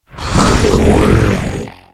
Cri de Torgamord dans Pokémon HOME.